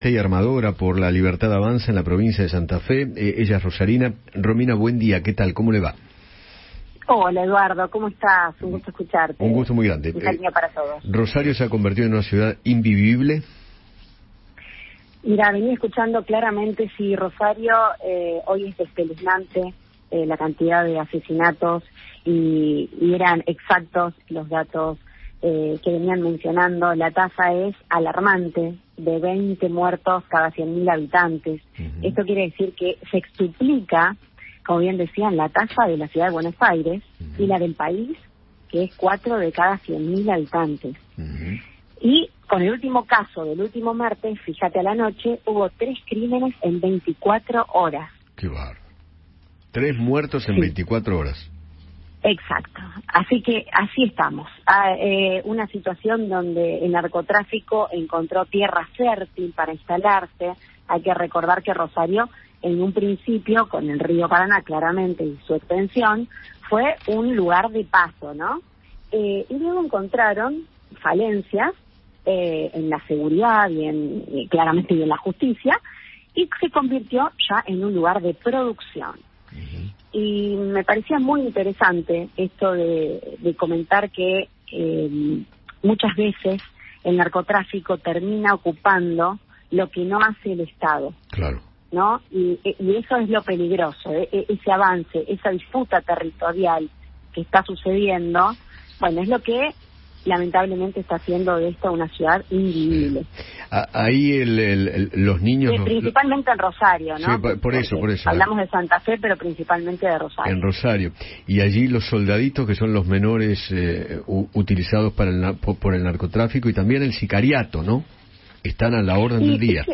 Romina Diez, integrante del partido La Libertad Avanza de Rosario, conversó con Eduardo Feinmann sobre la inseguridad y los hechos de violencia en aquella ciudad.